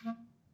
DCClar_stac_A#2_v1_rr2_sum.wav